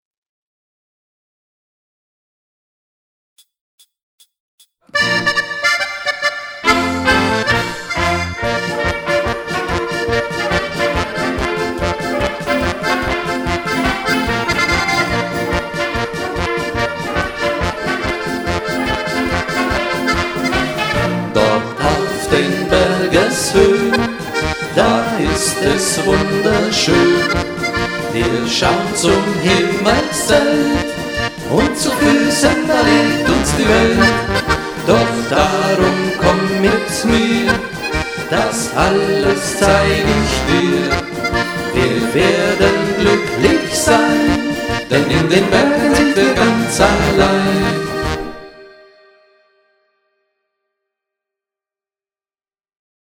• Coverband
• Allround Partyband